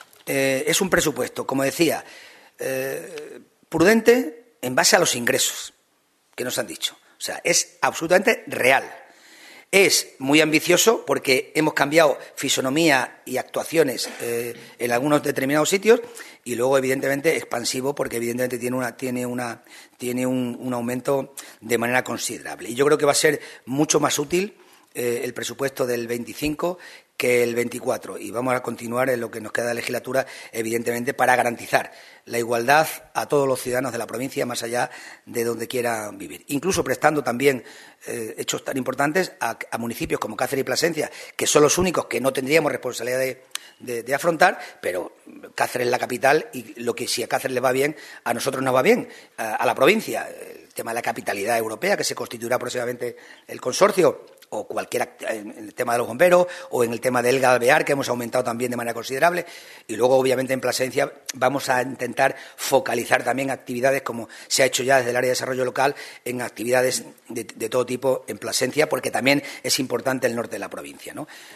CORTES DE VOZ
Miguel Ángel Morales sánchez_ Presidente de la Diputación de Cáceres_Definición Presupuestos